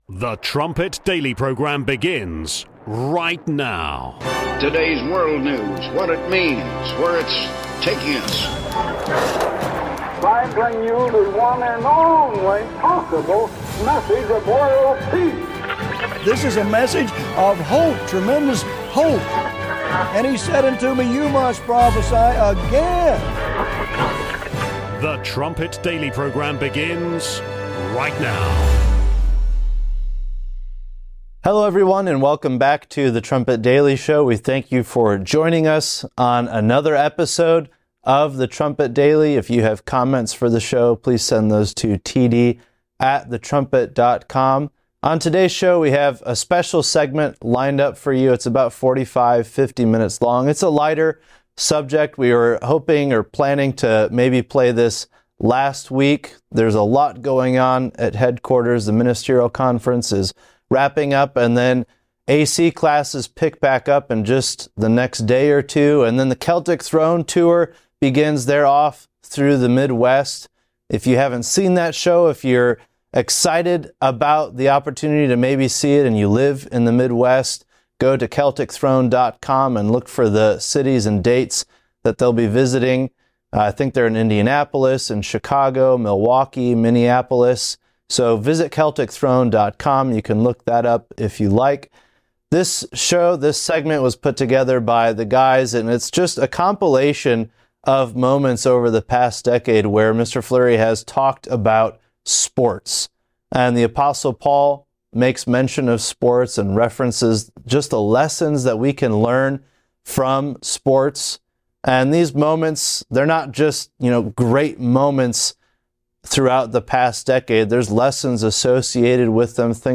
Trumpet Daily Radio Show brings you a deeper understanding of the Bible and how it connects to your world and your life right now.